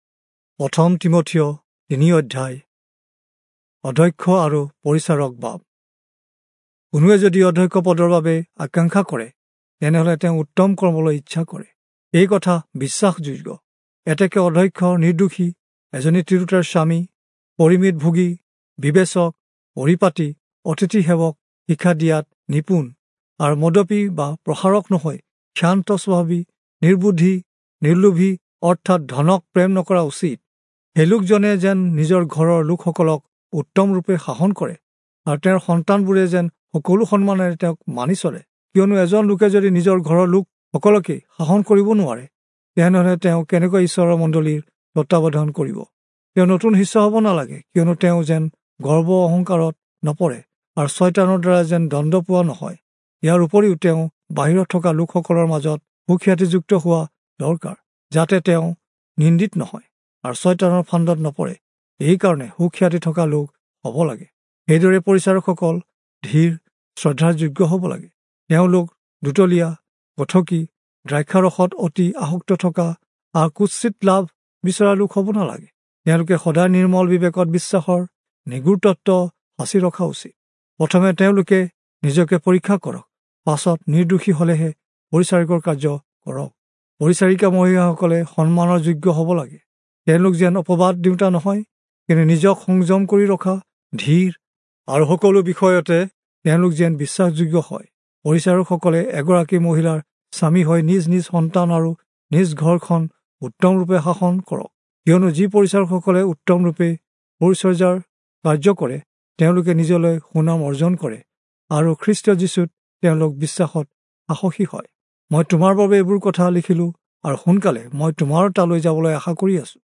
Assamese Audio Bible - 1-Timothy 2 in Irvas bible version